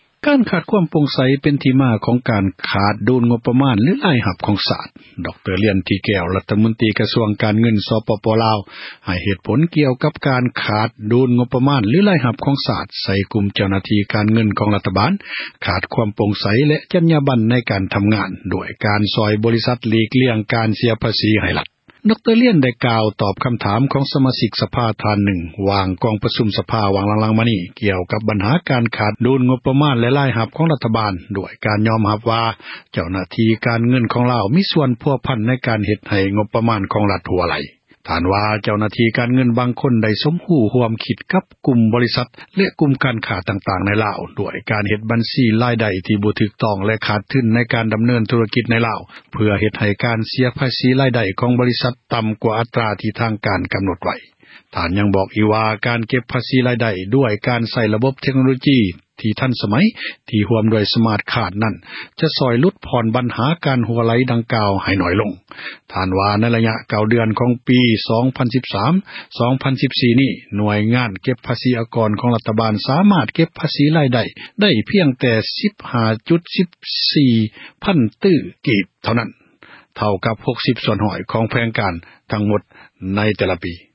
ດຣ ລຽນ ໄດ້ກ່າວ ຕອບຄຳຖາມ ຂອງ ສະມາຊິກ ສະພາ ທ່ານນື່ງ ໃນ ກອງປະຊຸມ ສະພາ ທີ່ ຜ່ານມານີ້ ກ່ຽວກັບ ບັນຫາ ການຂາດ ດູນ ງົປມານ ແລະ ຣາຍຮັບ ຂອງ ຣັຖບານ ດ້ວຍການ ຍອມຮັບ ວ່າ ເຈົ້າຫນ້າທີ່ ການເງີນ ຂອງລາວ ມີສ່ວນພົວພັນ ໃນການເຮັດ ໃຫ້ ງົປມານ ຂອງ ຣັຖບານ ຮົ່ວໄຫລ.